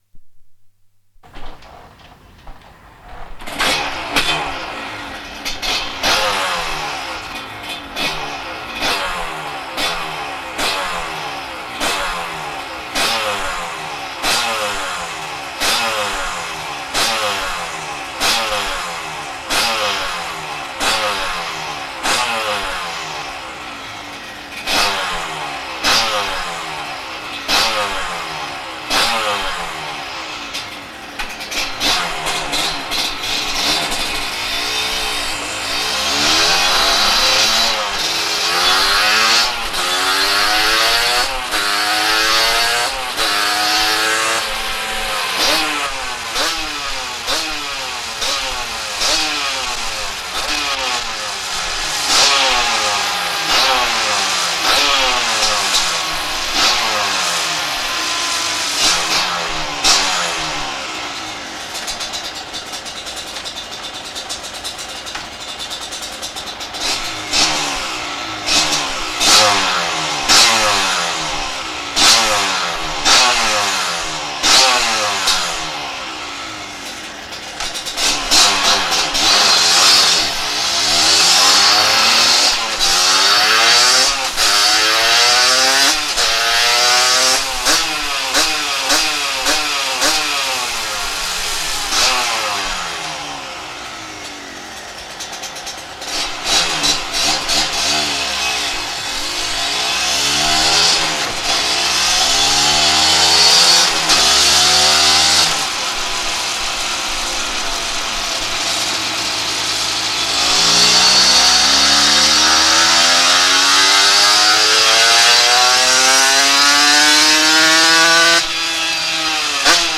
実は今回、店主の許可を得て、録音機（カセットテープ！＾＾；）を持ち込みパワーチェックの様子を収録する予定でした。
ノーマルチャンバーとプレシャスファクトリーチャンバーの音質を比較することで、「視覚」だけでなく「聴覚」でもみなさんに違いを感じていただこうと密かに目論んでいましたが･･････一発目のノーマルチャンバーの録音時に、レコーダーにトラブルが発生し、残念ながら録音できたのは2回目のプレシャスファクトリーチャンバーのみとなってしまいました。